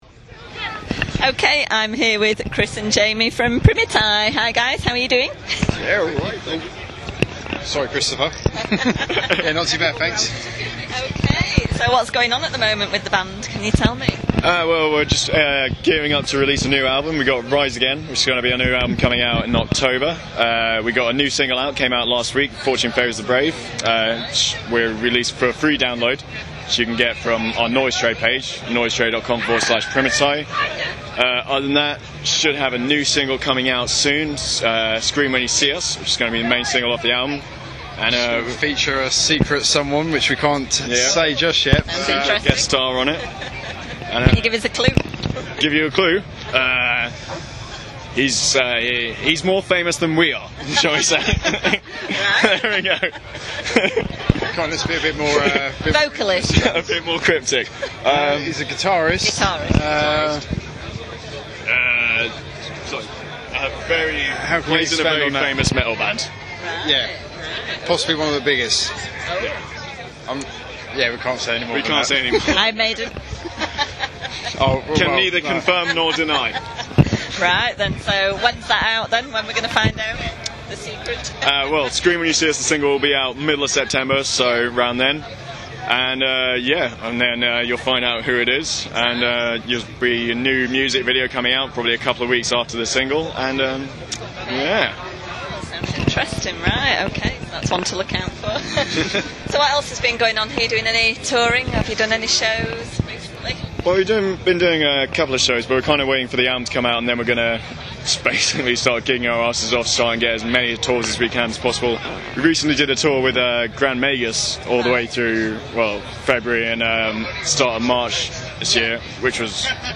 Interview With PRIMITAI: Discuss New Album, Special Guest